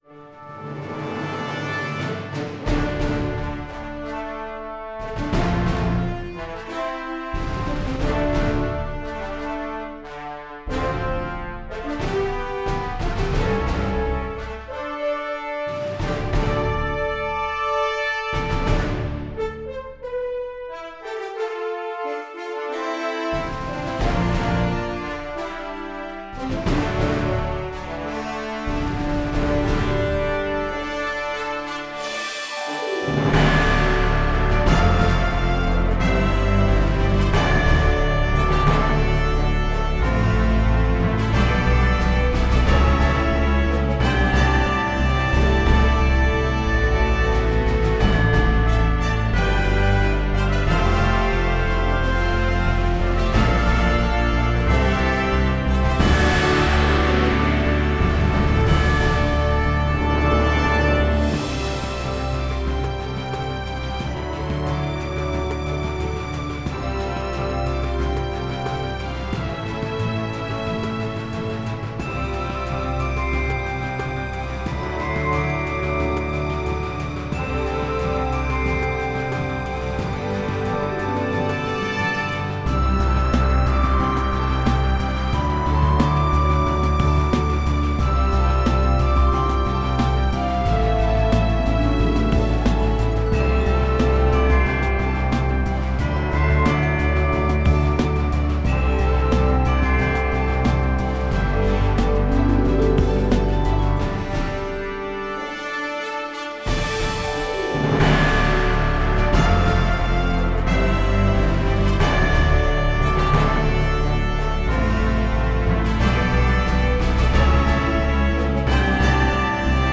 Perhaps if some inspiring theme song were composed to go with the new space department within the Pentagon we could get inspired and boldly go where no budget has gone before.